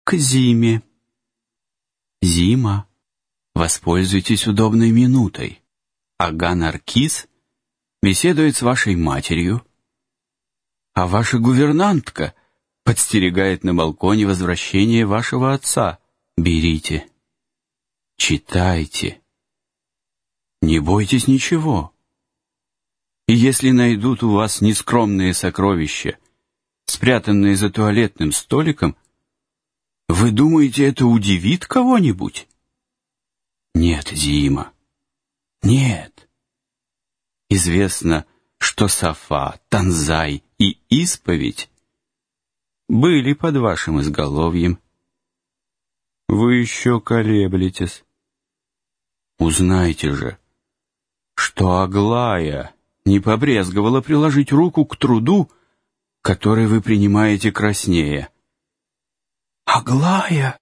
Аудиокнига Нескромные сокровища | Библиотека аудиокниг